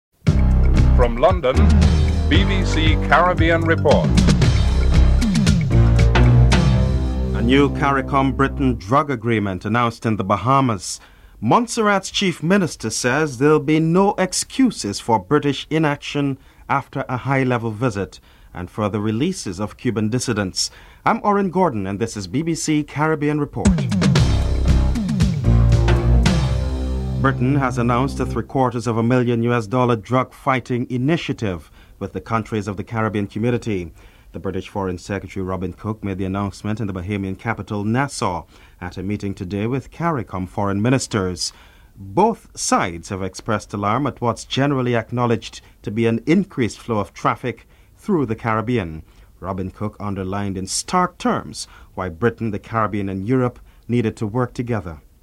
8. Recap of top stories (14:57-15:20)